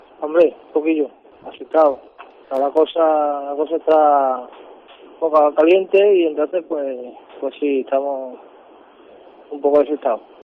Los trabajadores de los locales atracados: "Tenemos miedo"